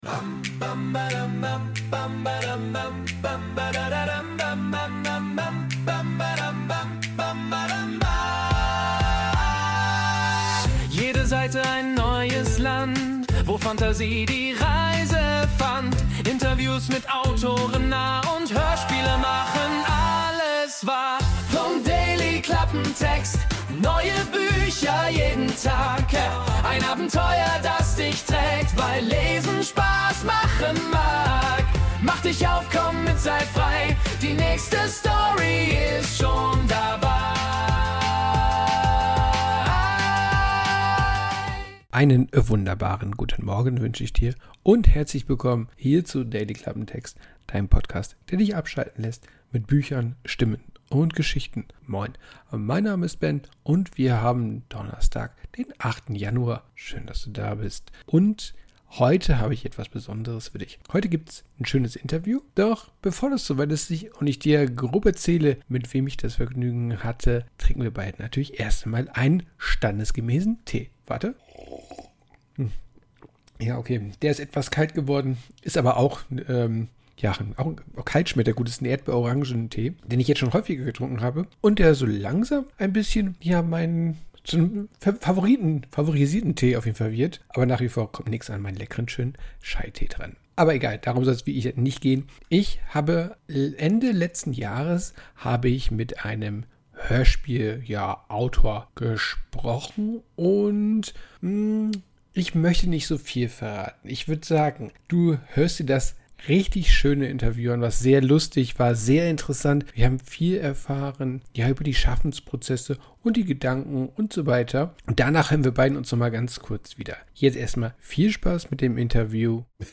Interview ~ Dailyklappentext Podcast